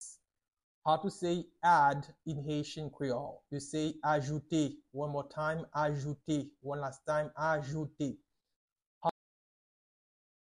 Pronunciation:
Listen to and watch “Ajoute” audio pronunciation in Haitian Creole by a native Haitian  in the video below:
2.How-to-say-Add-in-Haitian-Creole-–-Ajoute-with-pronunciation.mp3